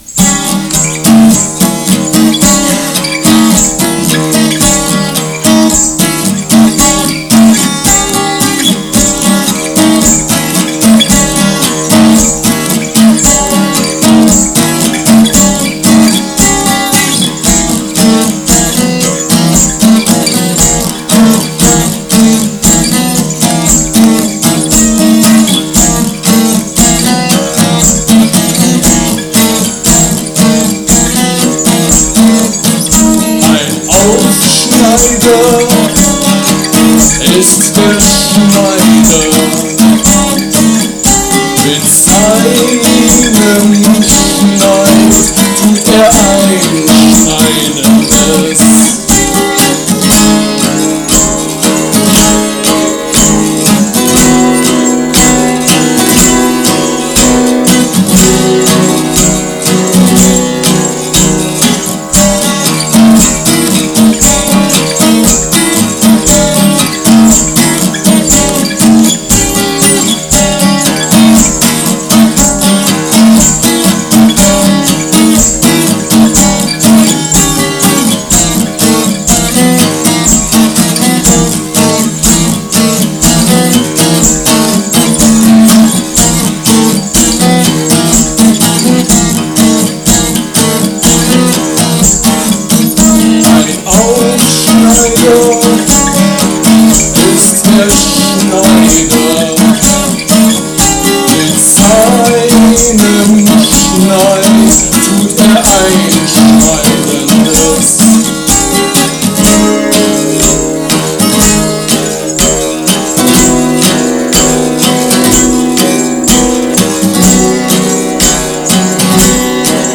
live 10.07.93 ibp München)Herunterladen